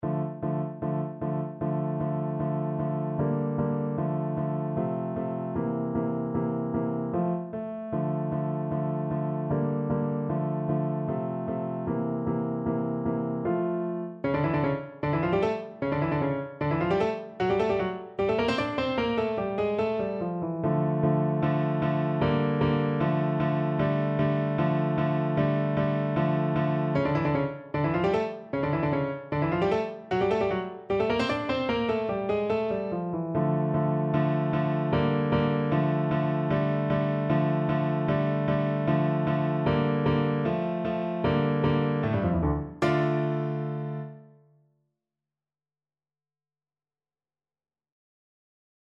Play (or use space bar on your keyboard) Pause Music Playalong - Piano Accompaniment Playalong Band Accompaniment not yet available transpose reset tempo print settings full screen
D minor (Sounding Pitch) (View more D minor Music for Cello )
Allegro scherzando (=152) (View more music marked Allegro)
2/4 (View more 2/4 Music)
Classical (View more Classical Cello Music)